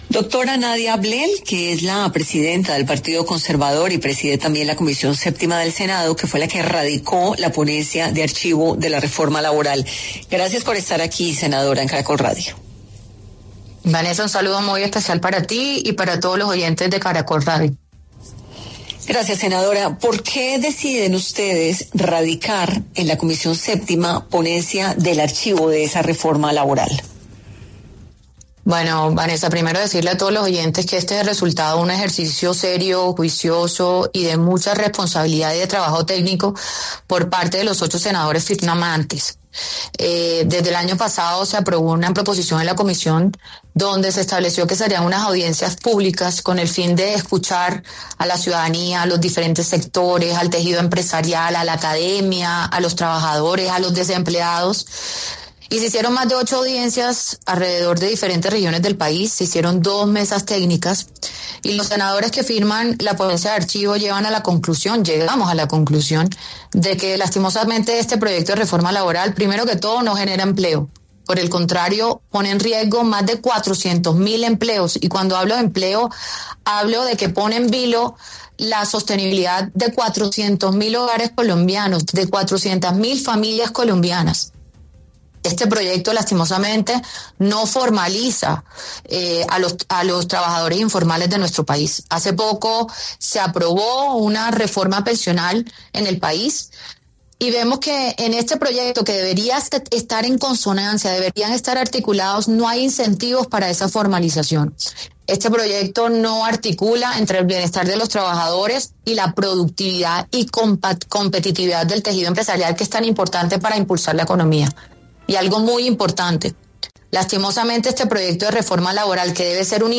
En 10AM de Caracol Radio estuvo Nadia Blel, presidenta de la Comisión VII del Senado, para hablar sobre los motivos del presidente Petro, para proponer una consulta popular para definir el futuro de la reforma laboral y de la reforma a la salud.